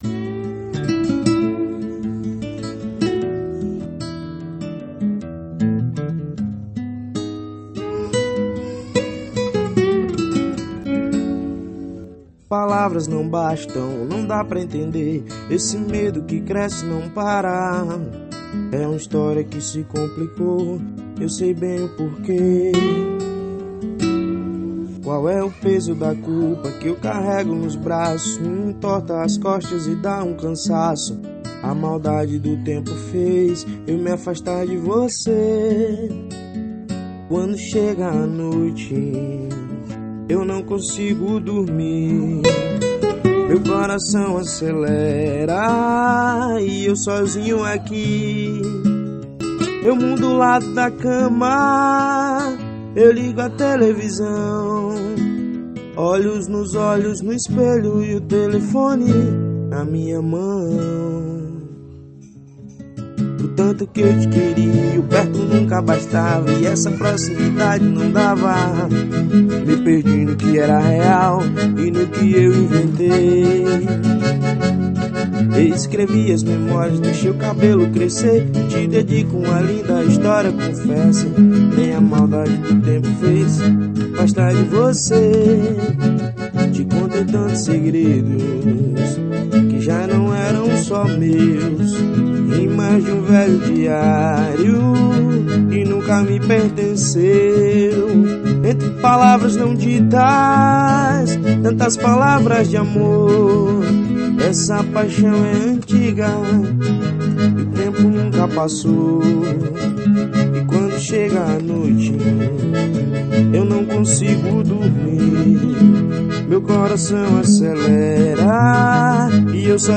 xote.